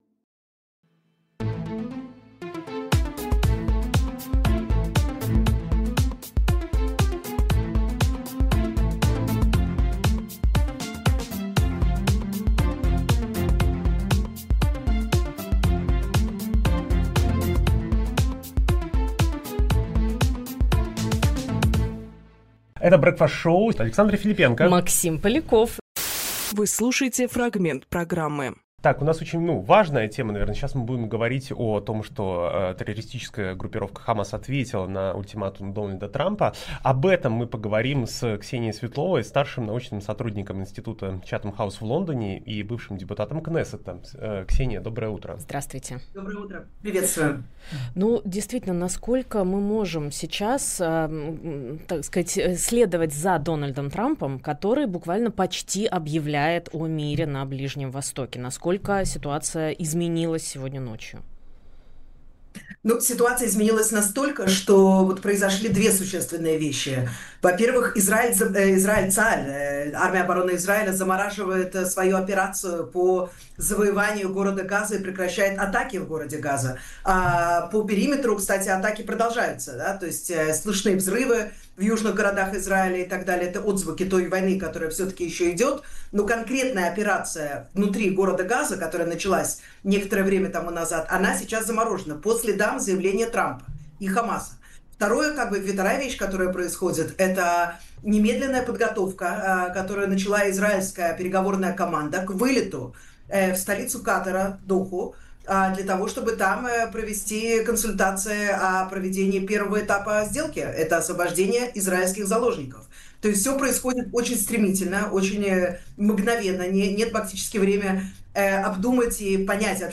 Фрагмент эфира 04.10.25